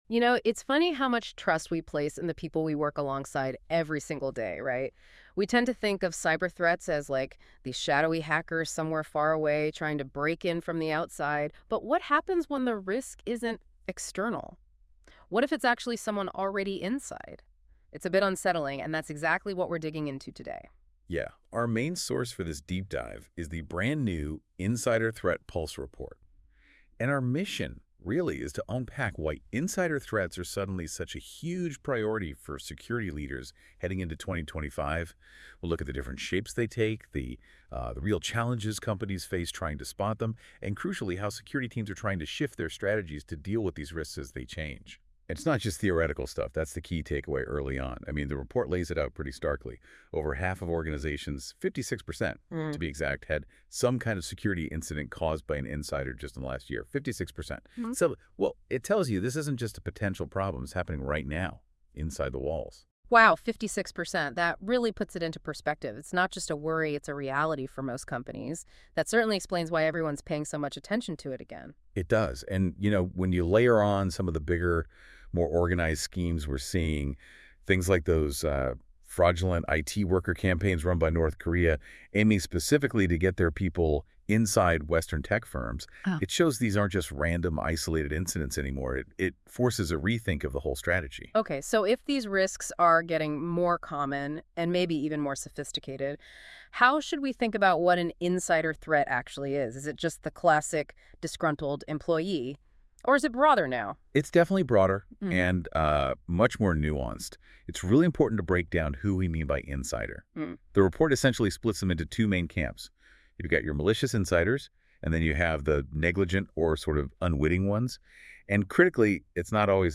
Audio source: Google NotebookLM